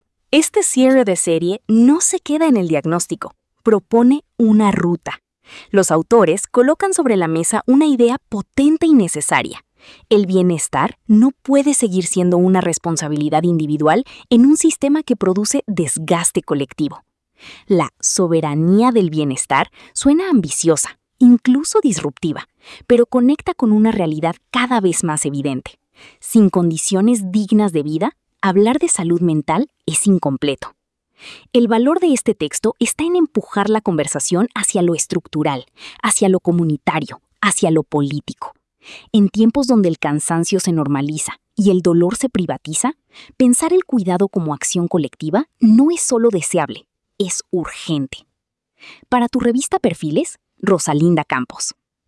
COMENTARIO EDITORIAL 🎙